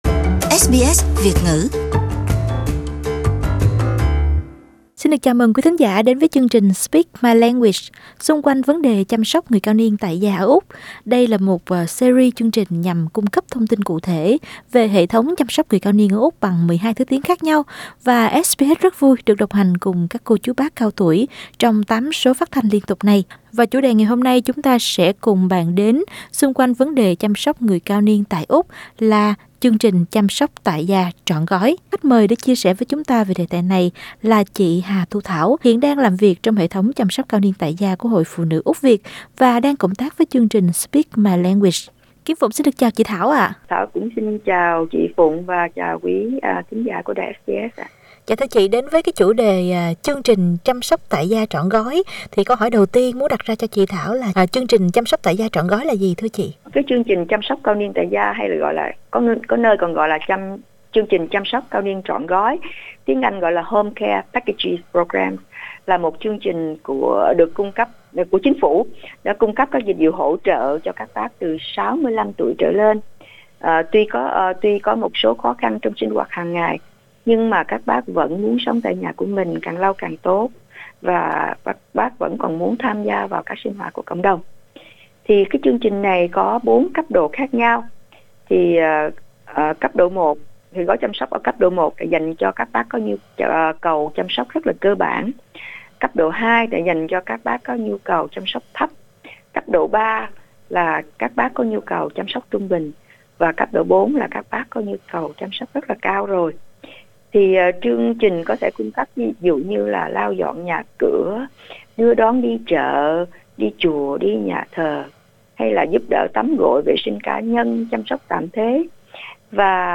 Chị đã có cuộc trò chuyện với SBS xung quanh chủ đề này: 1.